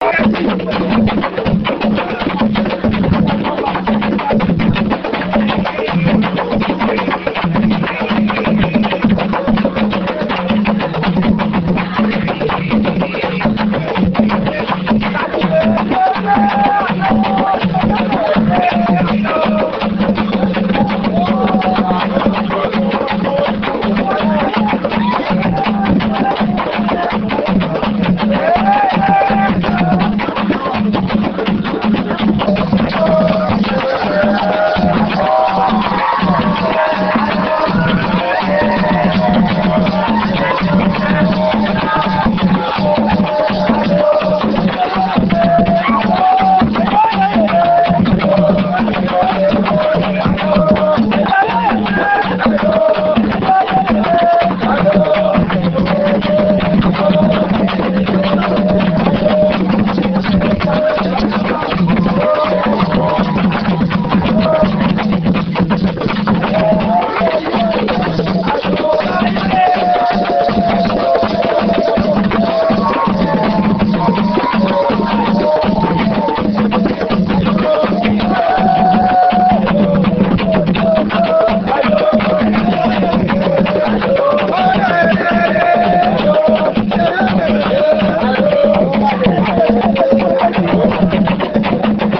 enregistrement durant une levée de deuil (Puubaaka)
danse : songe (aluku)
Genre songe
Pièce musicale inédite